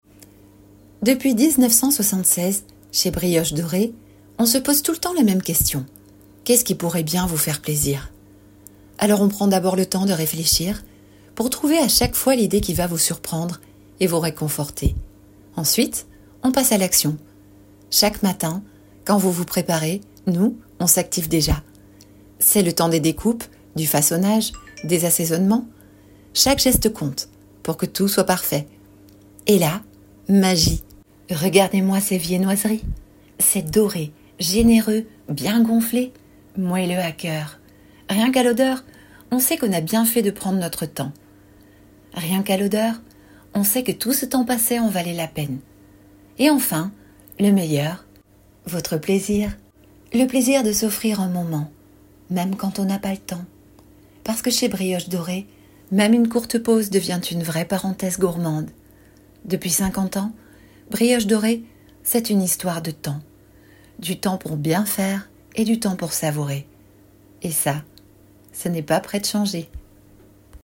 Test VO
7 - 50 ans - Mezzo-soprano